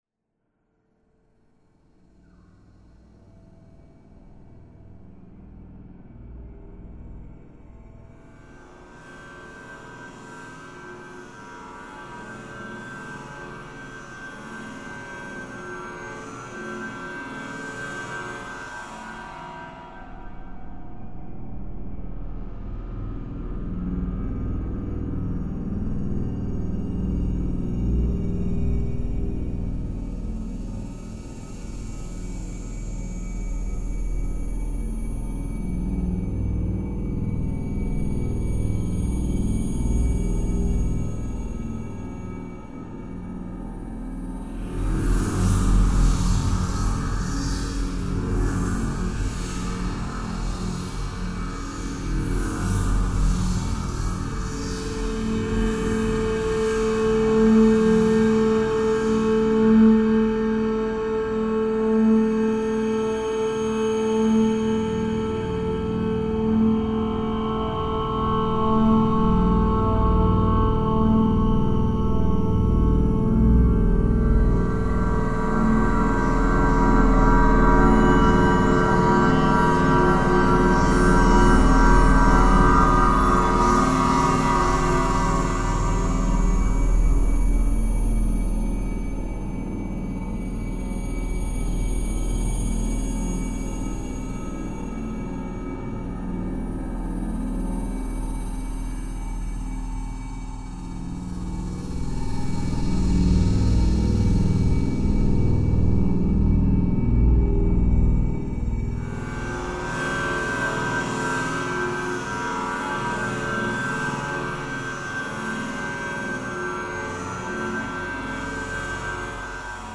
File under: Dark Ambient / Experimental